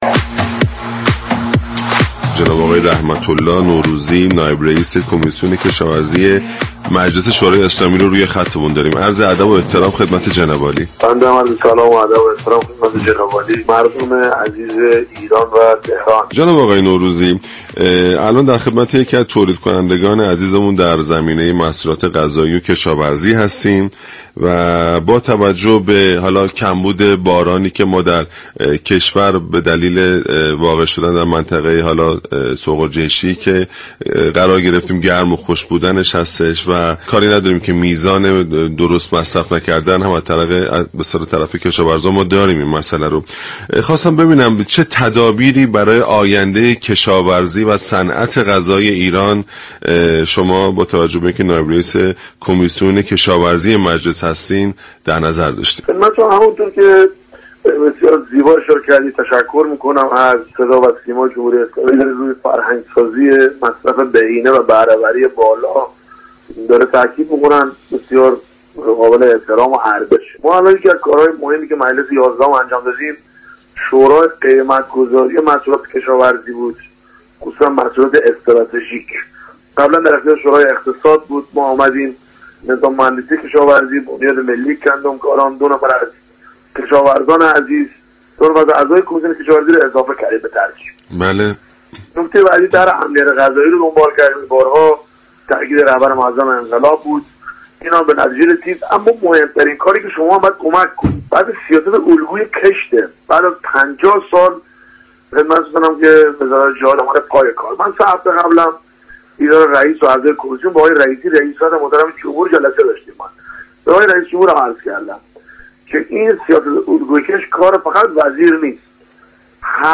به گزارش پایگاه اطلاع رسانی رادیو تهران؛ رحمت الله نوروزی نایب رئیس كمیسیون كشاورزی، آب و منابع طبیعی مجلس شورای اسلامی در گفت و گو با برنامه "بر بلندای همت" رادیو تهران در پاسخ به این پرسش كه ایران در منطقه جفرافیایی گرم و خشك قرار گرفته است چه تدابیری برای آینده كشاورزی و صنعت غذایی كشور اندیشیده شده است؟